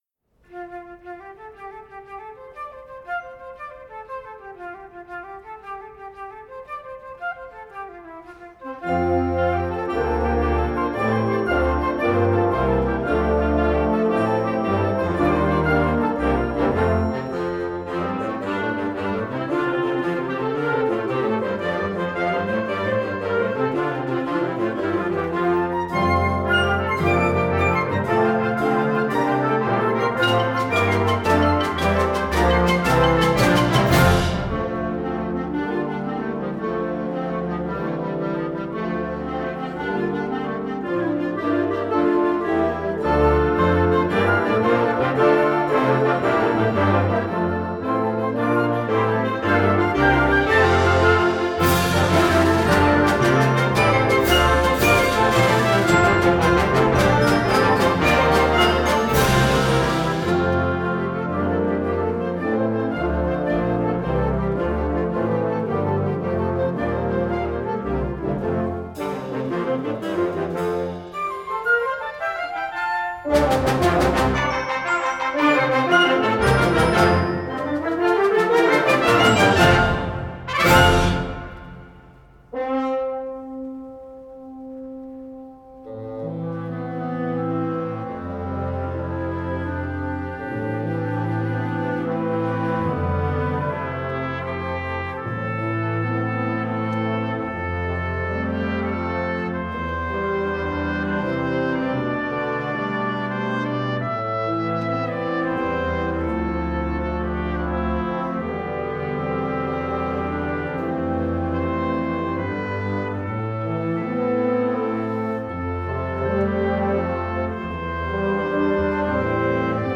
Gattung: Suite für Blasorchester
Besetzung: Blasorchester
ist eine fantastische Suite irischer Melodien.